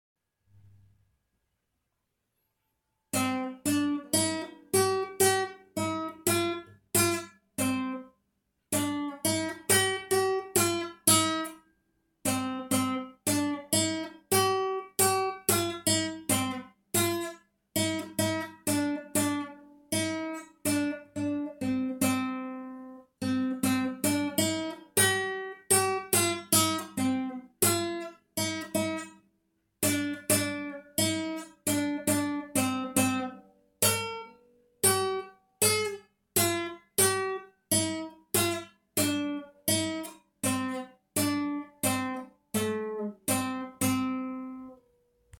Solado no Violão ( Instrumental)